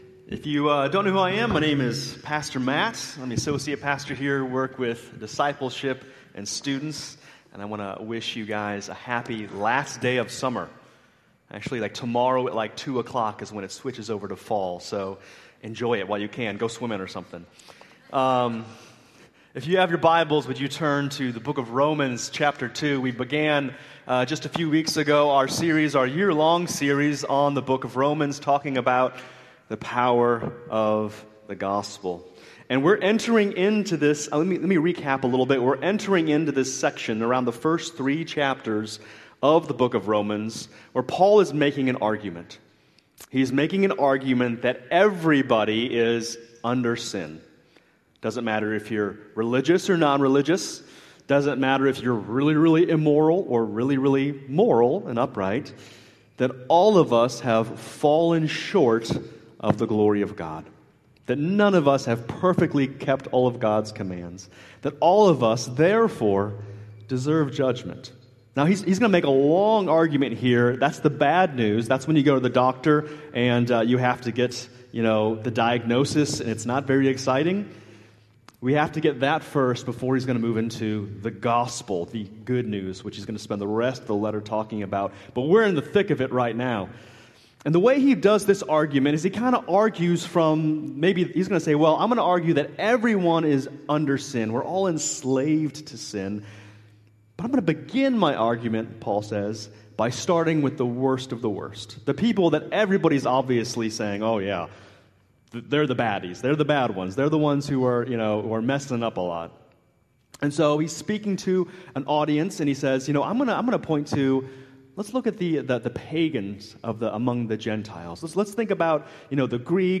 Living Hope Church Worship Service - September 21, 2025